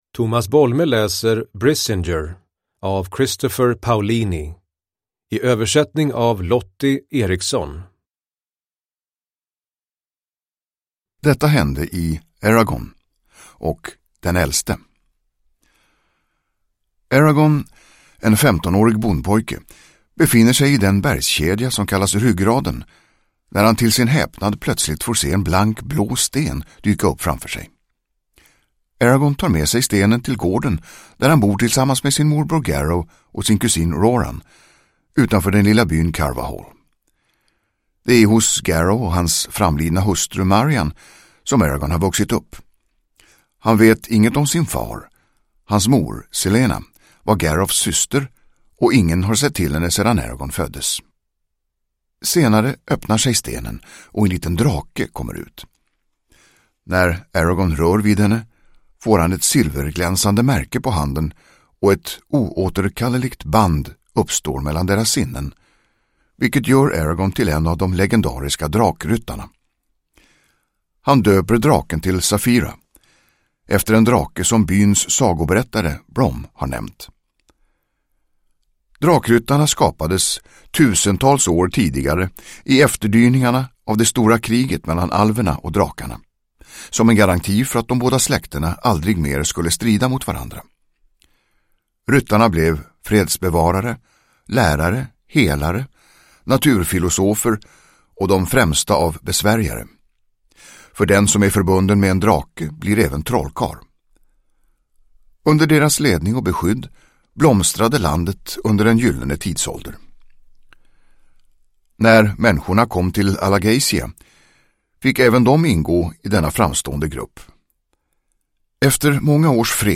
Brisingr – Ljudbok – Laddas ner
Uppläsare: Tomas Bolme